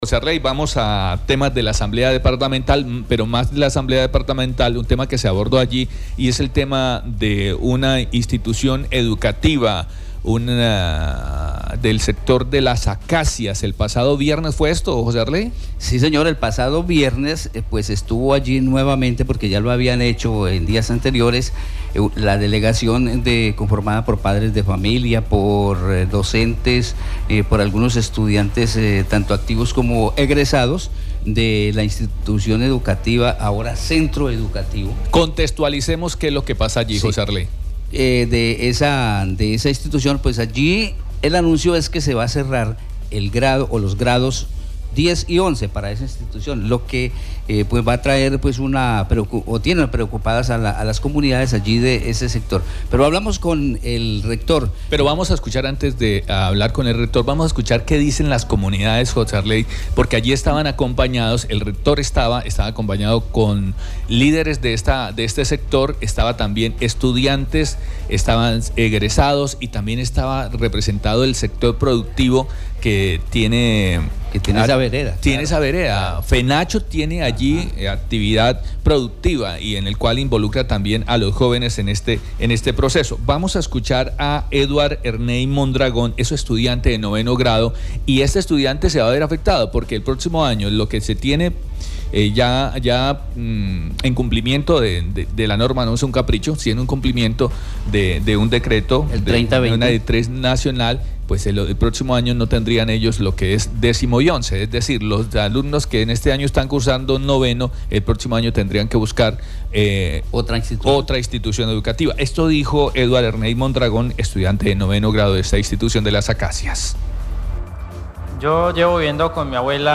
Los padres de familia y estudiantes activos y egresados expusieron ante la Asamblea Departamental, que esta medida les complica la vida, en el sentido que los estudiantes que terminan el grado 9º tendrán que buscar otras instituciones educativas, lo que consideran traería deserción de estudiantes de grados menores.